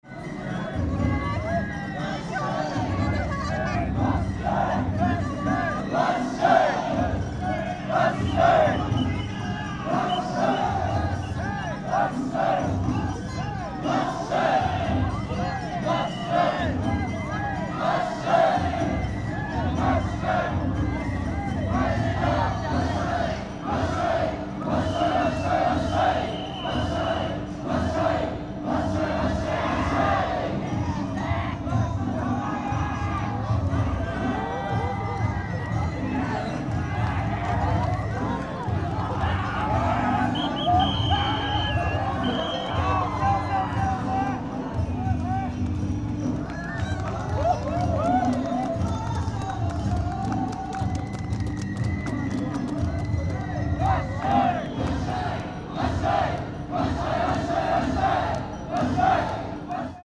In front of Machi-naka Square
At the end of Waraji dance parade, O-waraji (The biggest Japanese Sandal) came.